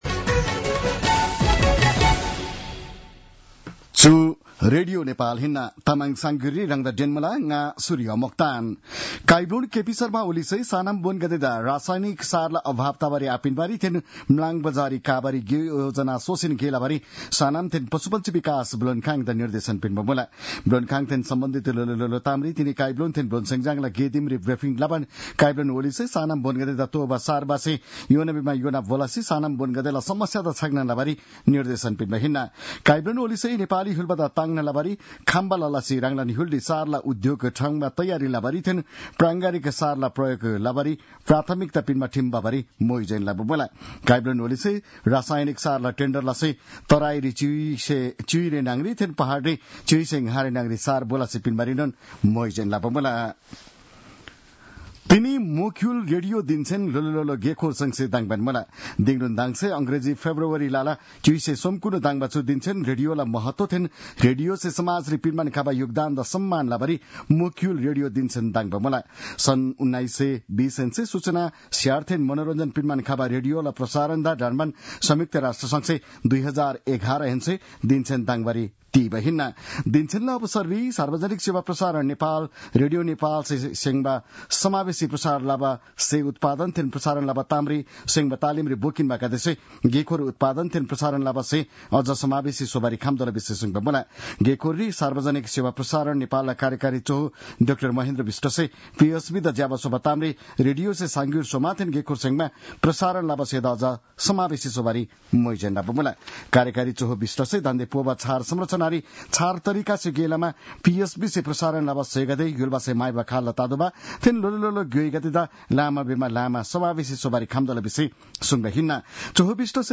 तामाङ भाषाको समाचार : २ फागुन , २०८१
Tamang-news-11-01.mp3